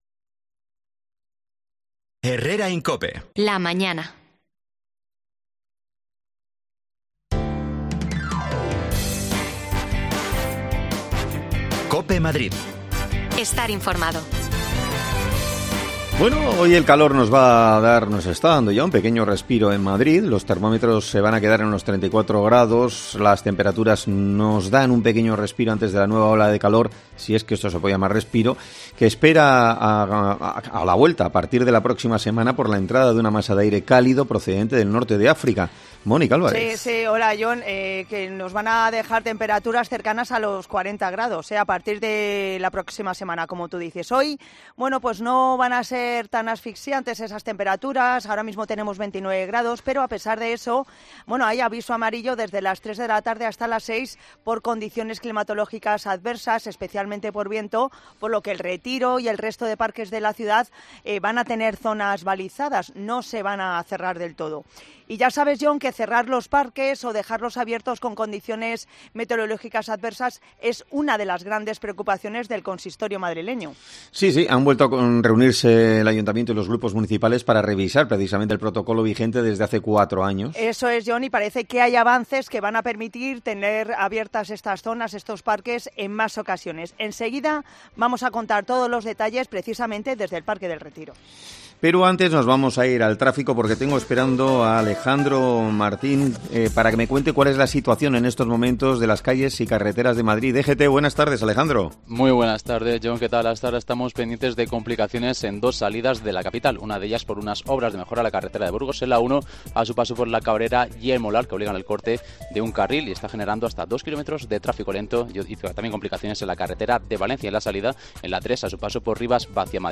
Avances importantes en el protocolo para cerrar los parques de la ciudad en caso de condiciones metereológicas adversas. Te contamos cuáles son desde el Retiro
Te contamos las últimas noticias de la Comunidad de Madrid con los mejores reportajes que más te interesan y las mejores entrevistas, siempre pensando en el ciudadano madrileño.